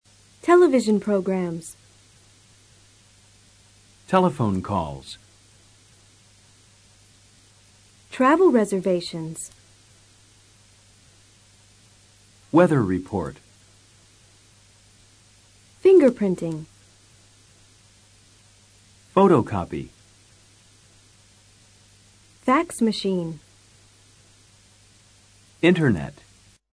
Escucha, en los próximos dos audios, la acentuación de estos sustantivos.